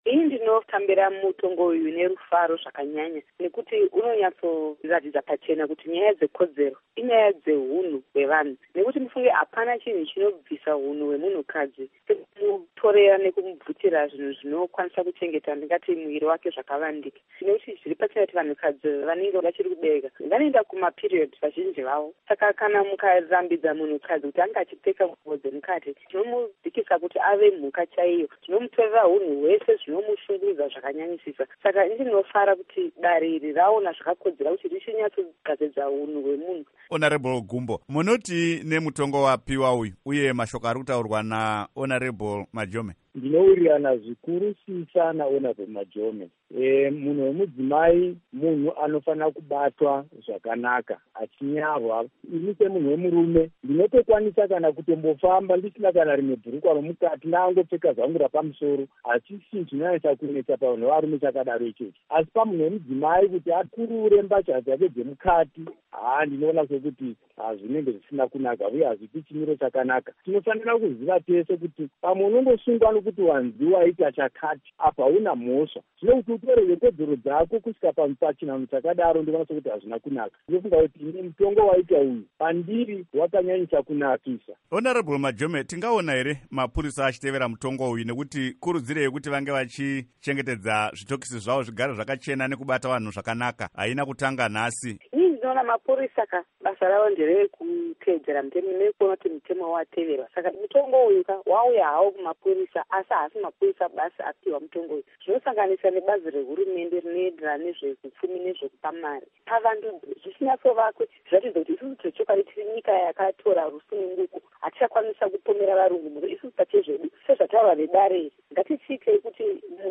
Hurukur naMuzvare Jessie Majome pamwe naVaJoram Gumbo